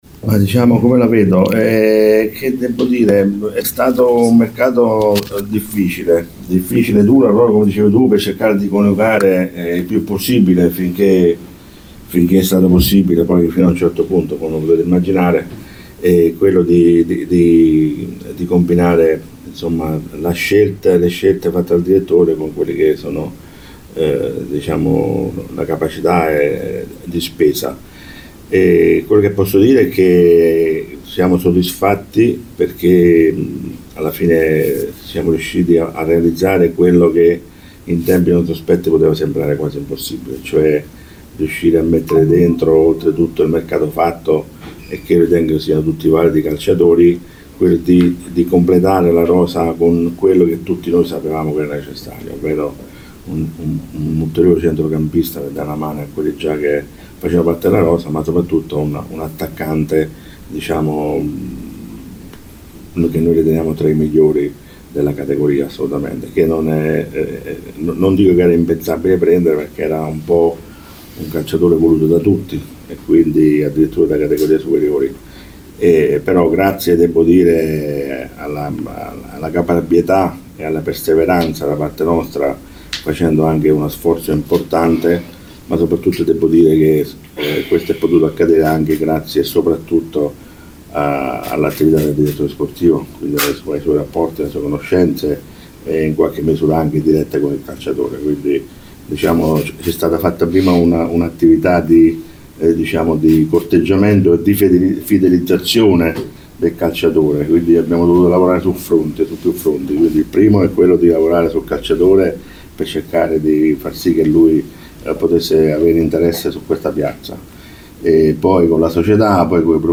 nella conferenza stampa tenuta questa mattina